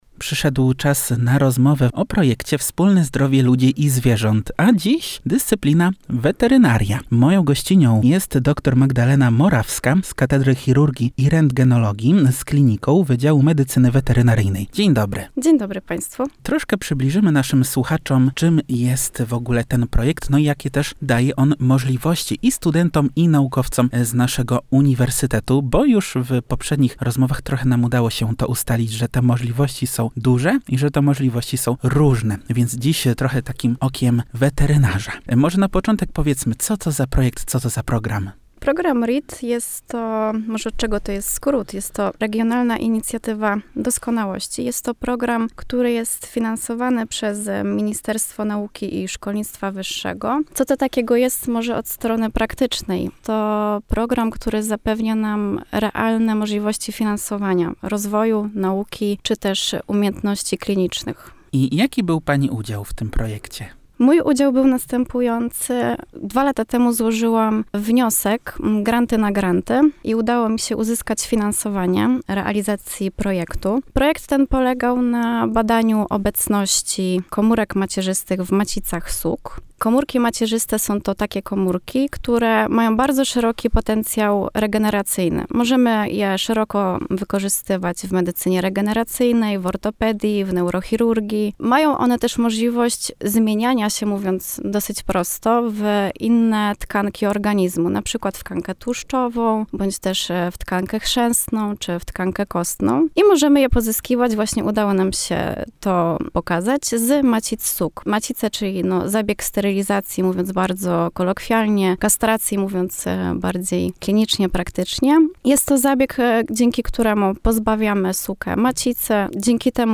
– mówiła w studiu Radia UWM FM